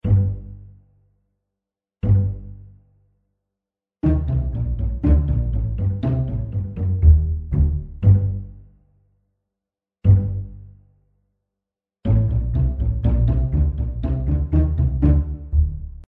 Звук игры рукой на контрабасе